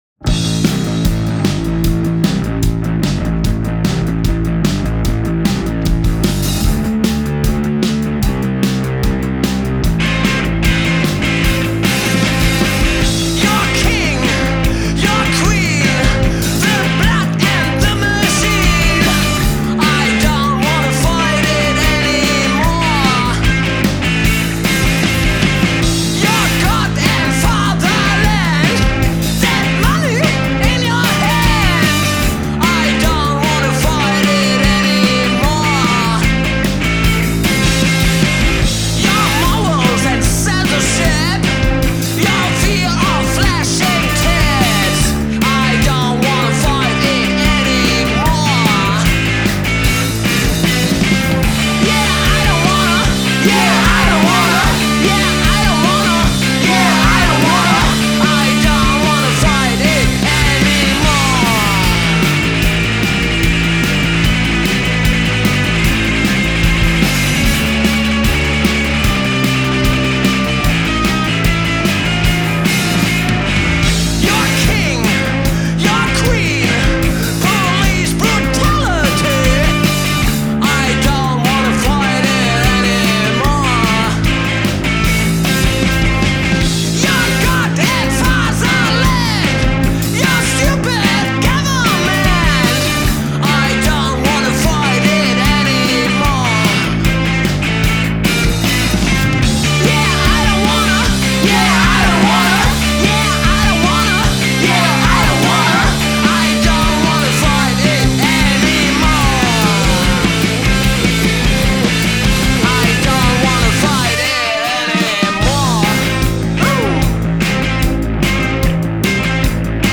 snotty, pumping PostPunk